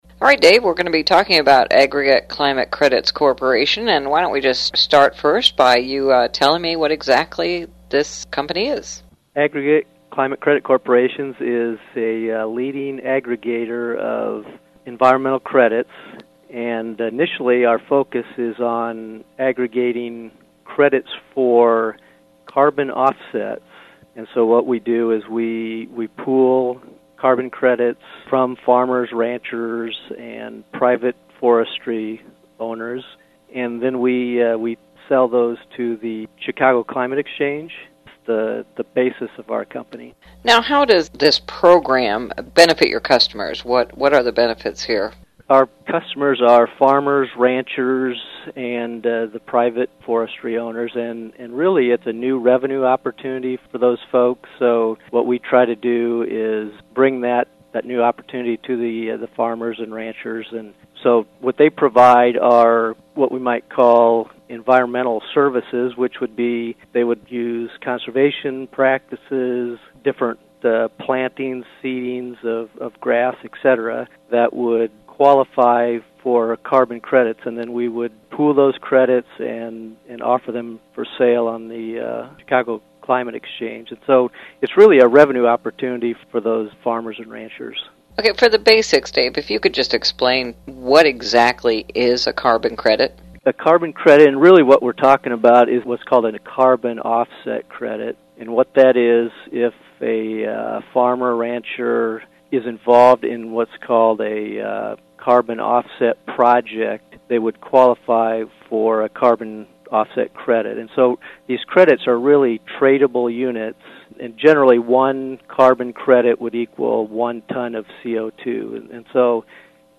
Ag Media Summit, Agribusiness, Audio